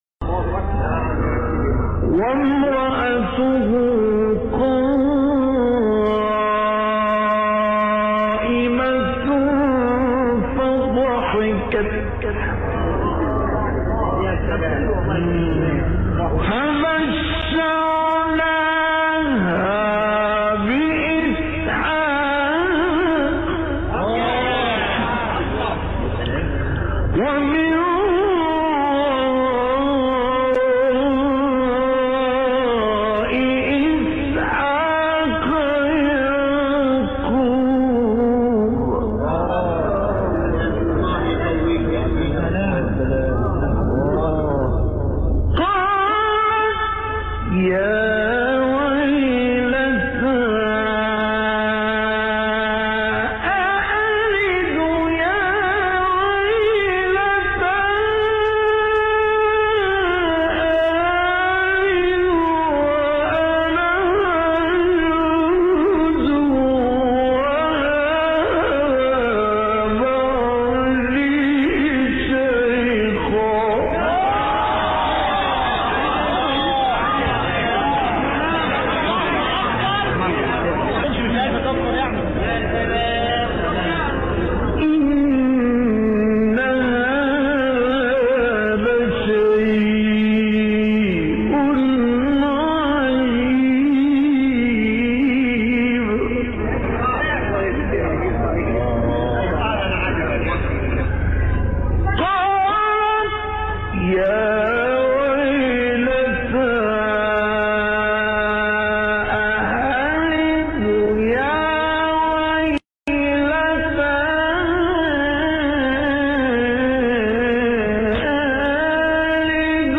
تلاوت 70-73 سوره هود مصطفی اسماعیل | نغمات قرآن
مقام : رست * چهارگاه